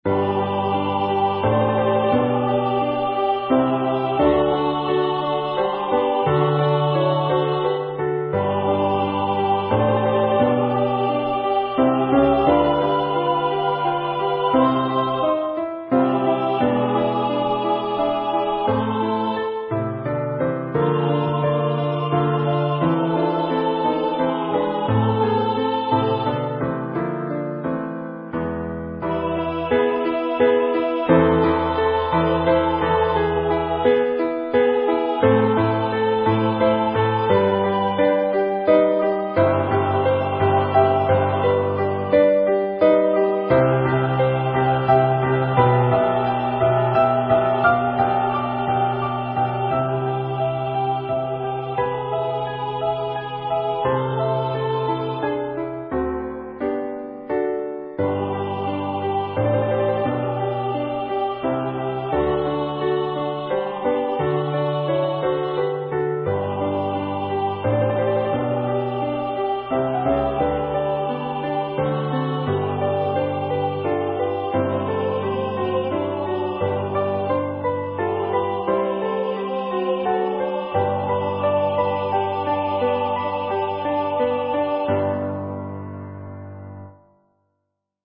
Practice Files: Soprano:     Alto:     Tenor:     Bass:
Number of voices: 4vv   Voicing: SATB
Genre: SacredMass
Instruments: Piano